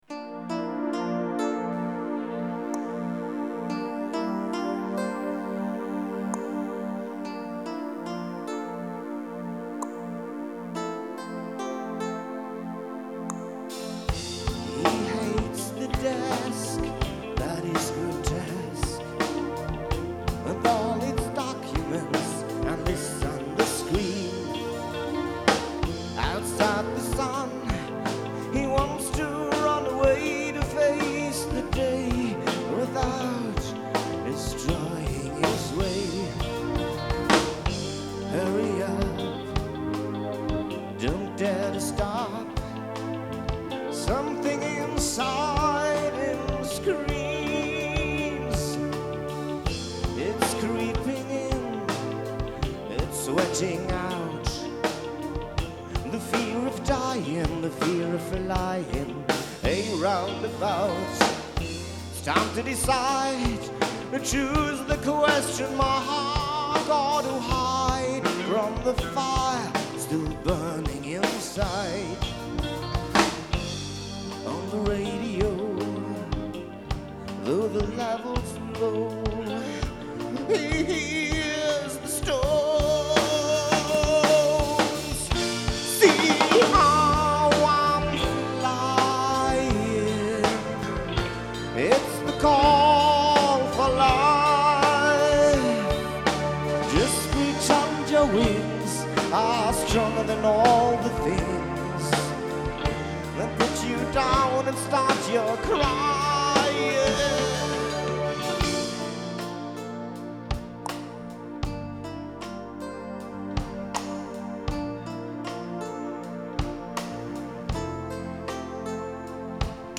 vocals
keyboards
drums
bass guitar